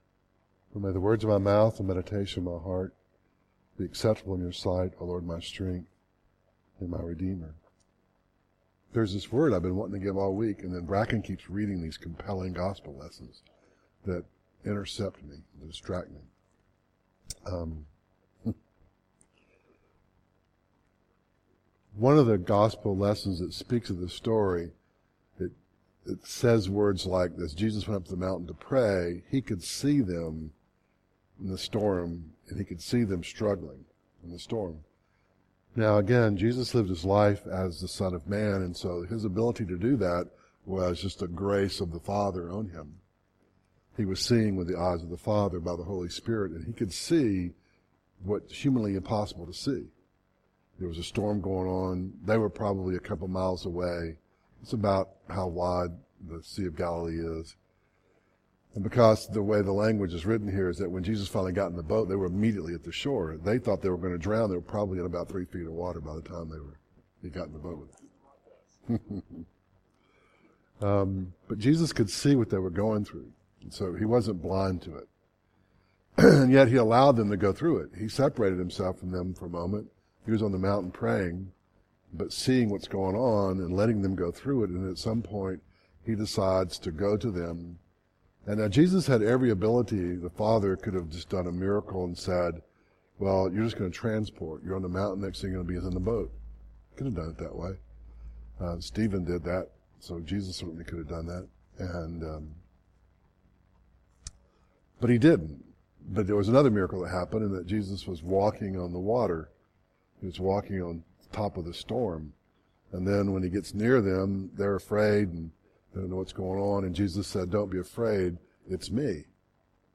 Mark 6:47-52 Service Type: Devotional